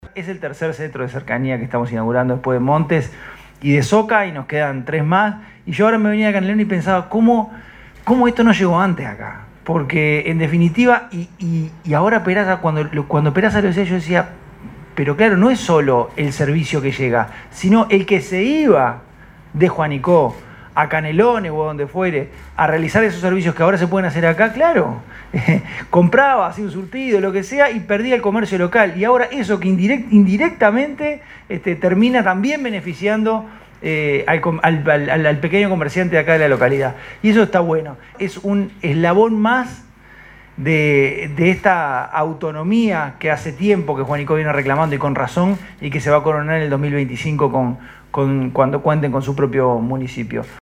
francisco_legnani_secretario_general.mp3